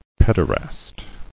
[ped·er·ast]